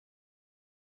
Soundscape Overhaul / gamedata / sounds / monsters / rat / eat_1.ogg
eat_1.ogg